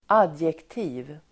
Uttal: [²'ad:jekti:v]